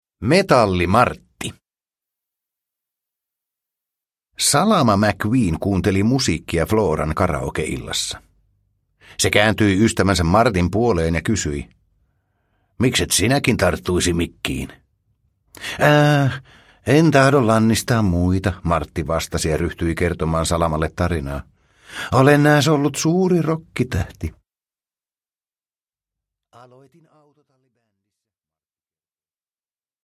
Pixar Autot. Metalli-Martti – Ljudbok – Laddas ner